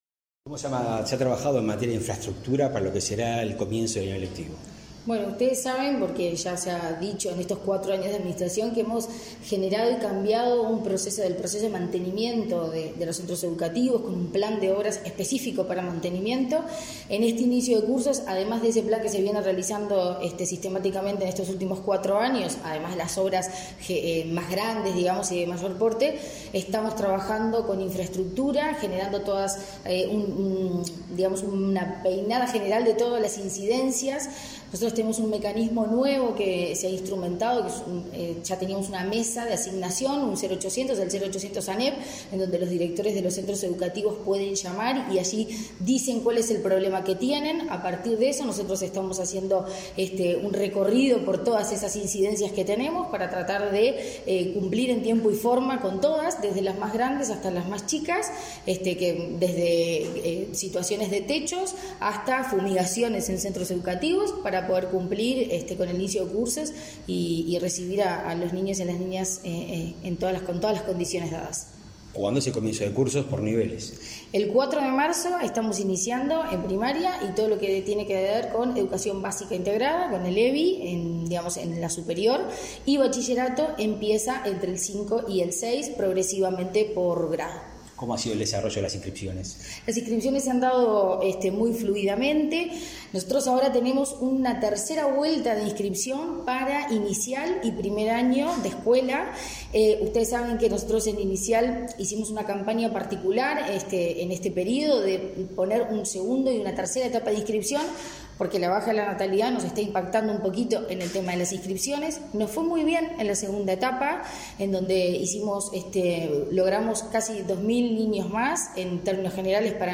Entrevista a la presidenta de la ANEP, Virginia Cáceres